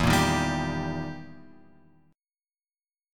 EmM7b5 chord {0 1 1 0 x 3} chord